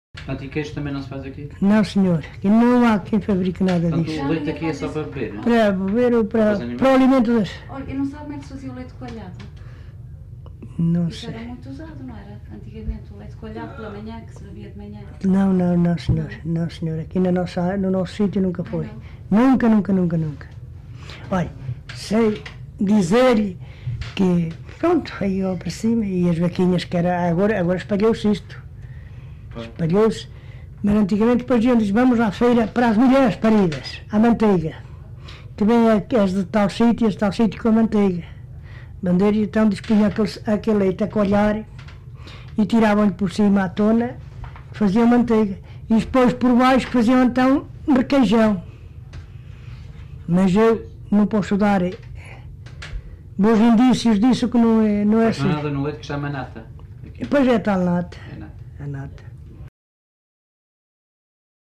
LocalidadeSanto André (Montalegre, Vila Real)